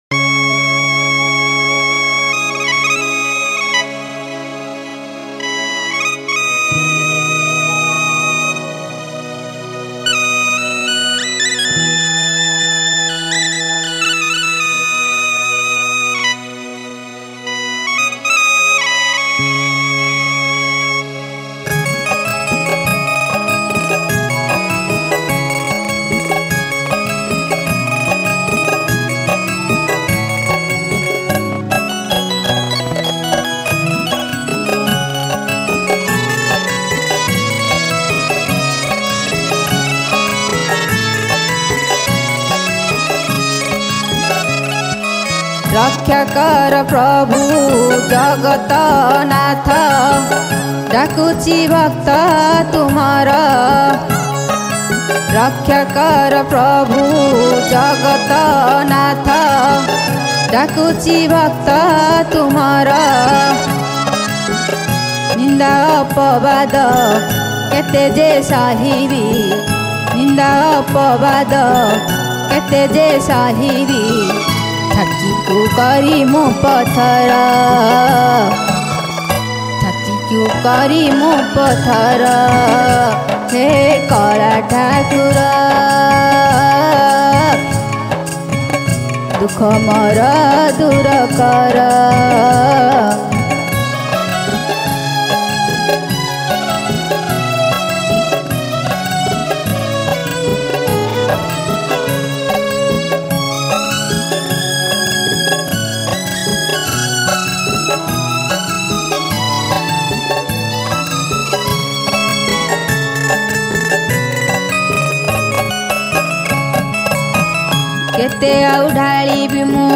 Ratha Yatra Odia Bhajan